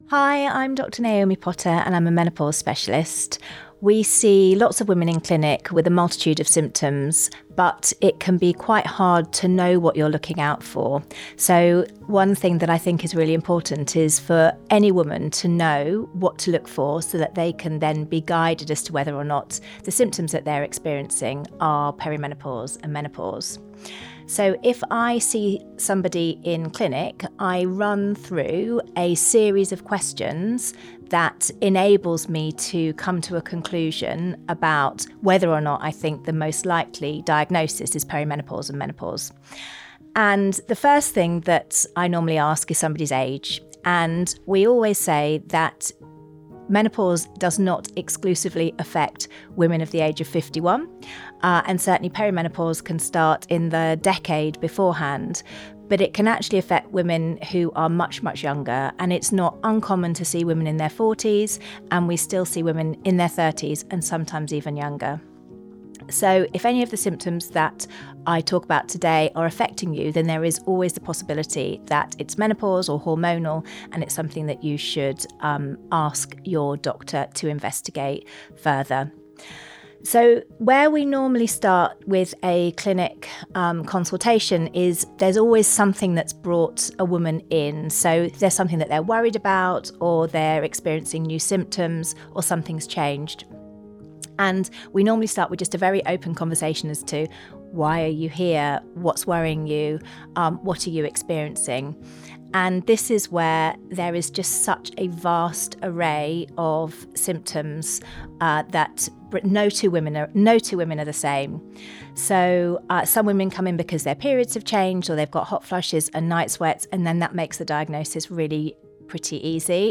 Part open-clinic, part talk show, this podcast is for all women seeking insight, reassurance, empathy, answers and laughter while navigating midlife.
Guests share their personal stories, open up about their difficulties, and laugh about some of the more bewildering challenges that come with mid-life.